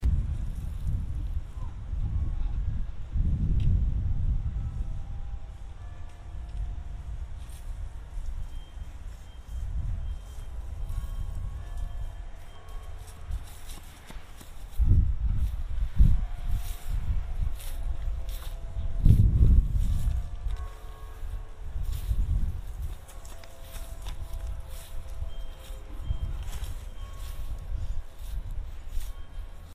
Grove ambient 5.mp3 Wind, a dancer's running footsteps go by, bells, and more construction machinery beeps